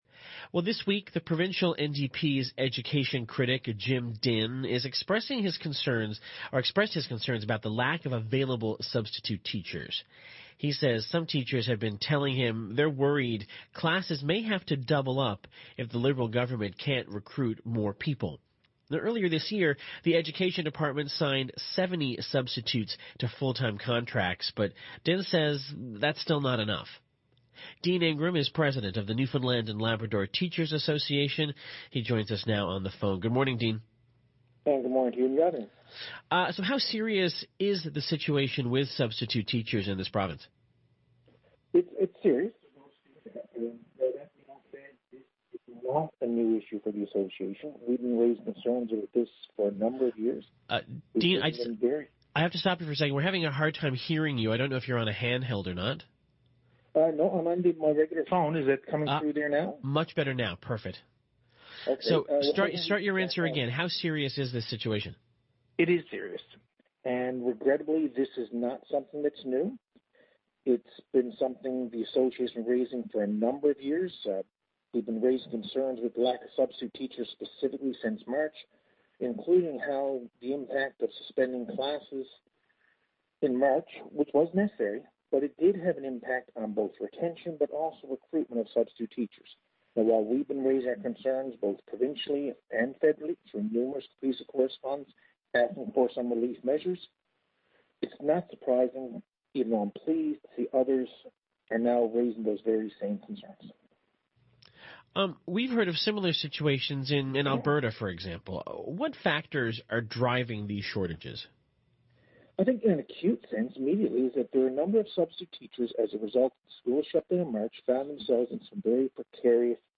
Media Interview - CBC NL Morning - Nov. 19, 2020